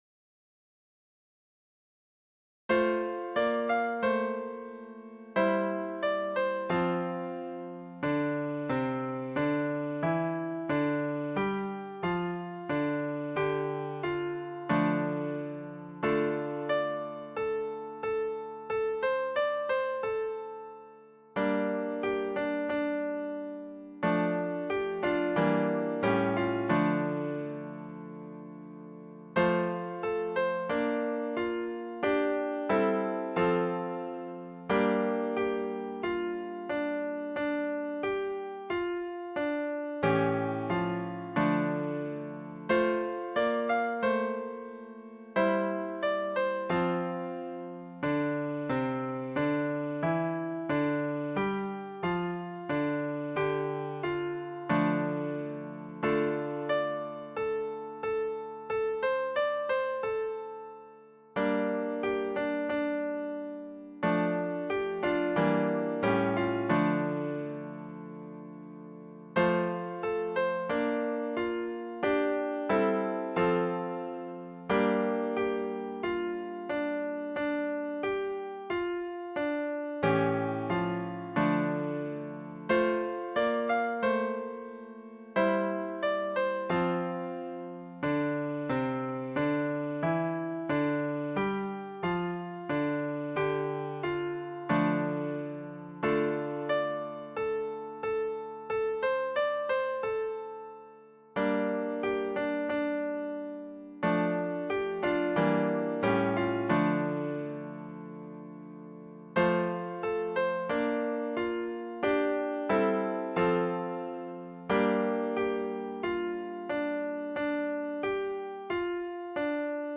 伴奏
示唱
曲调委婉深沉，有刺透心灵之感。